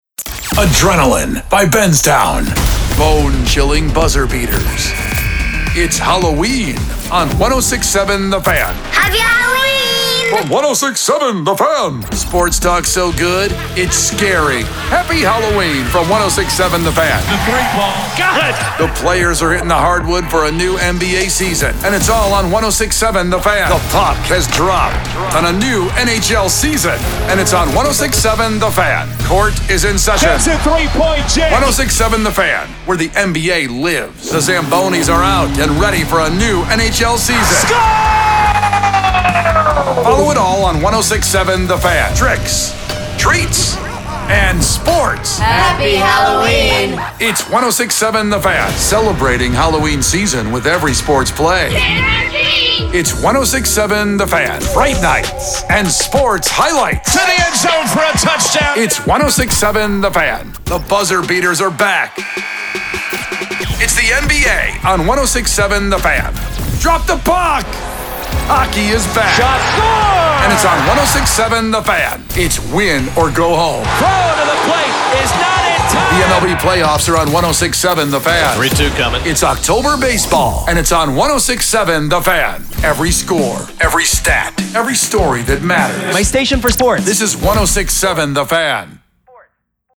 SPORTS
Containing shelled produced promos and sweepers, ramploops, branded song intros, artist IDs, listener drops, song hooks, musicbeds, individual imaging workparts, and more.